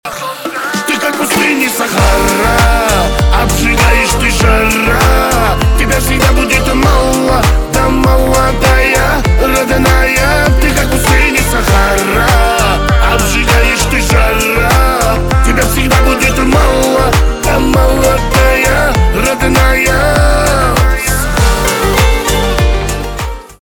поп
кавказские , романтические , битовые , гитара